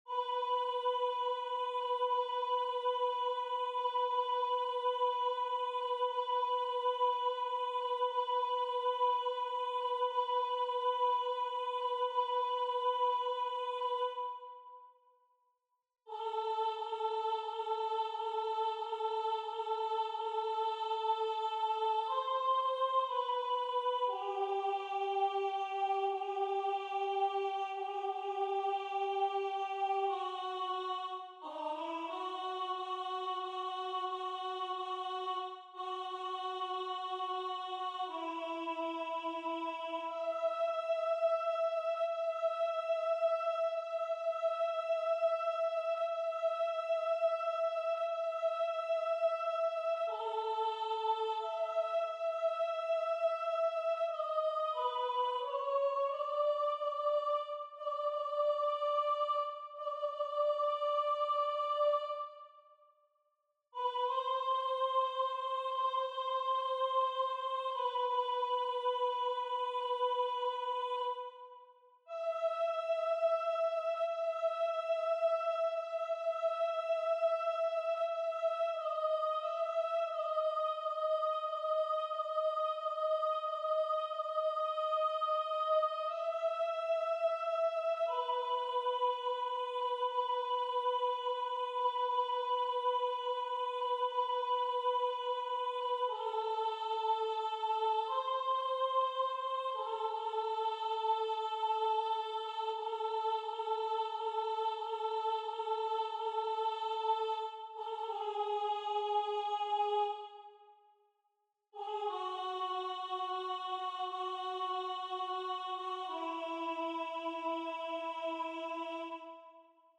MP3 versions rendu voix synth.
Soprano